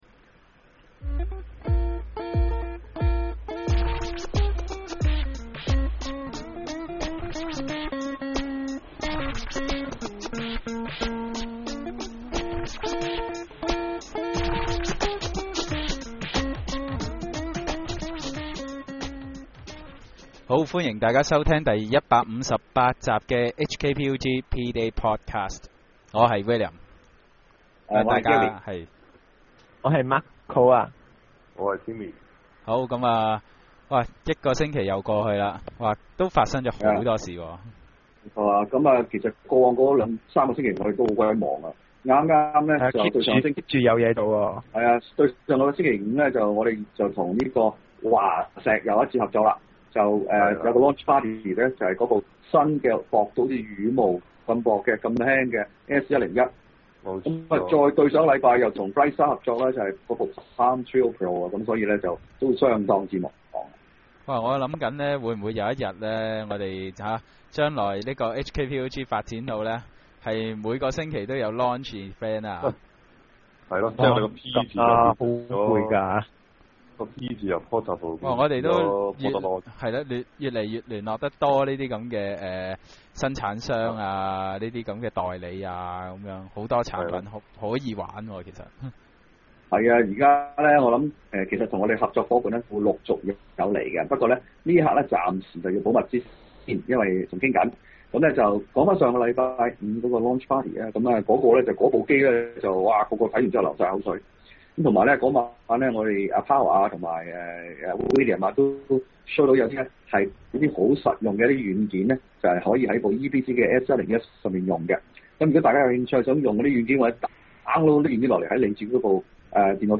今集主持們會一起探討。